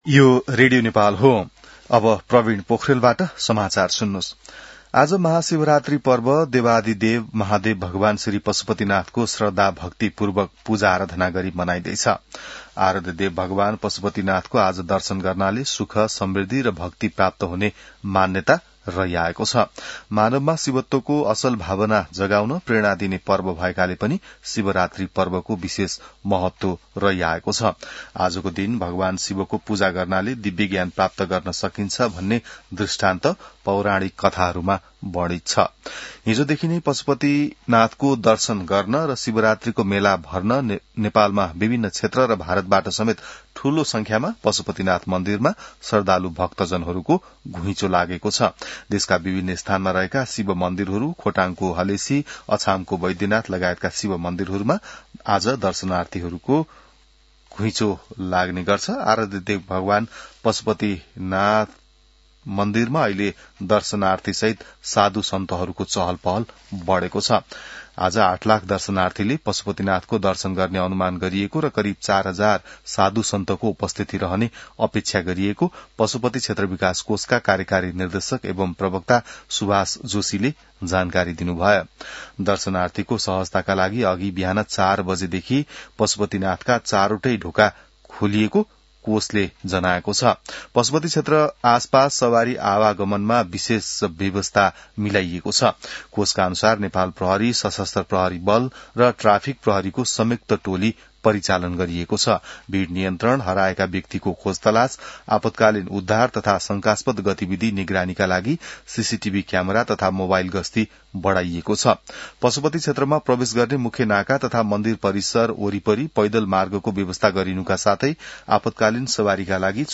बिहान ६ बजेको नेपाली समाचार : ३ फागुन , २०८२